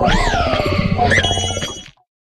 Cri de Garde-de-Fer dans Pokémon HOME.